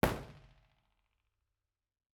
IR_EigenmikePL001R1_processed.wav